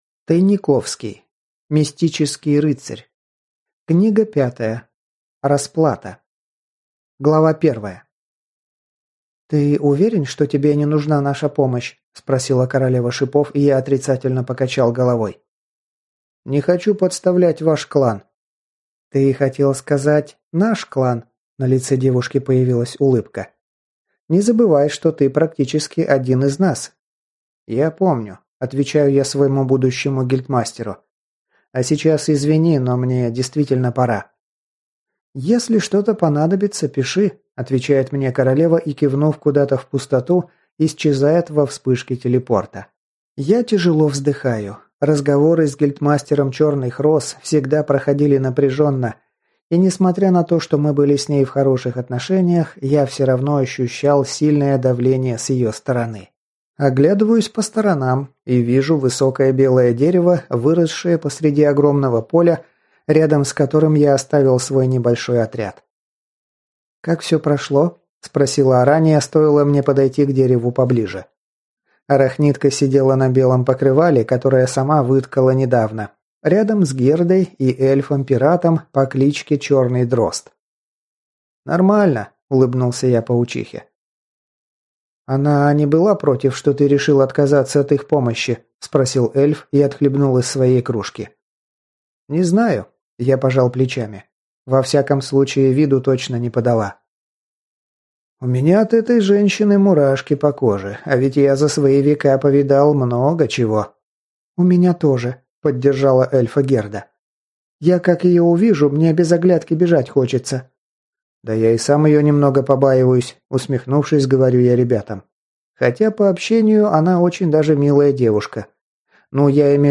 Аудиокнига Расплата | Библиотека аудиокниг